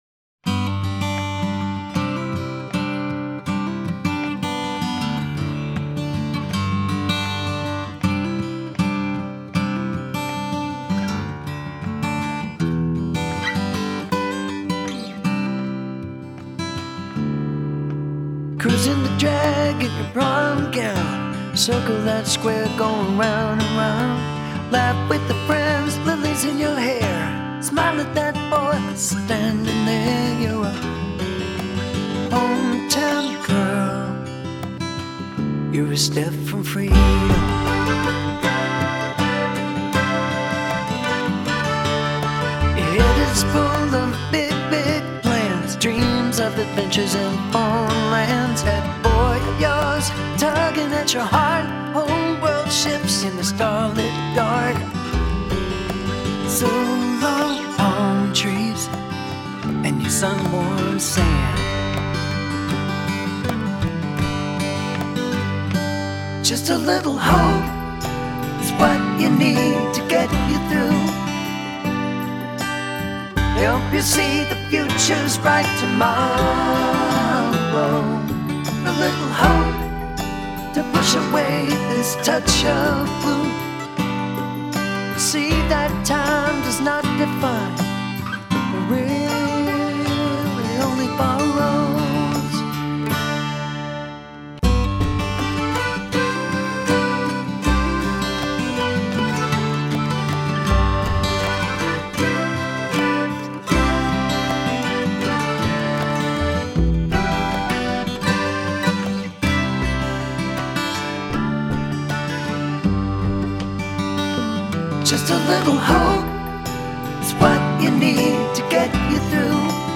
Americana music
accordion